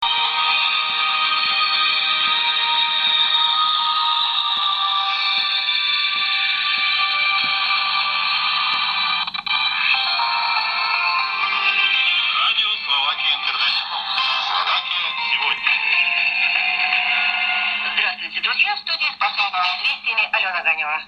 Radio Slovakia International via WRN Abu Dhabi
Station ID